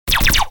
lasers.wav